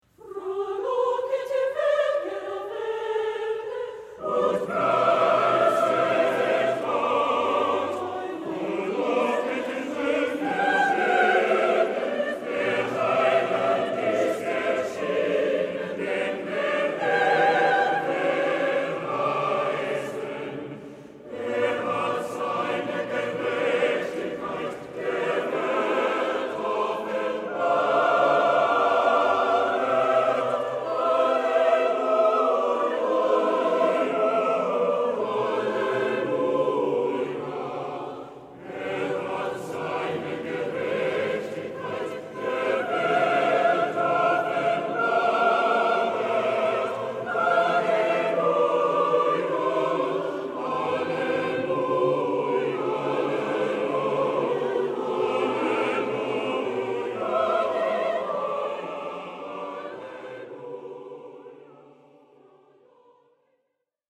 Voicing: SSAATTBB